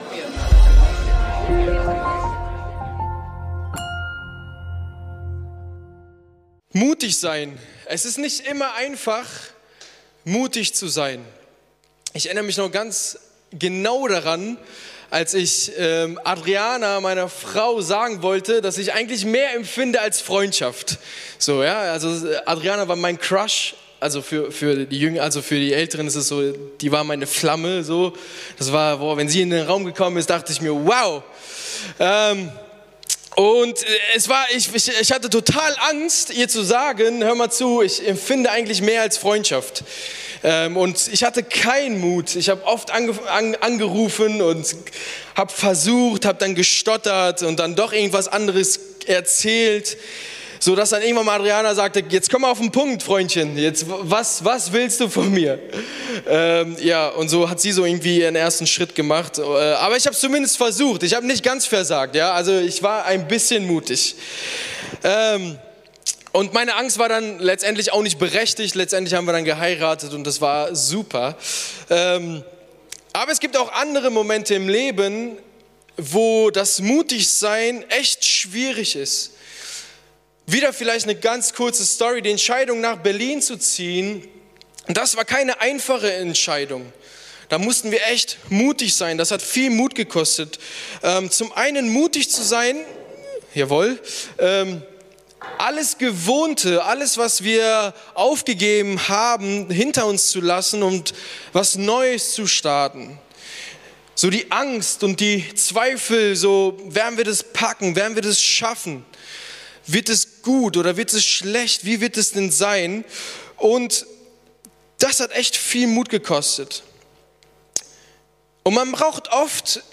Fasse Mut ~ Predigten der LUKAS GEMEINDE Podcast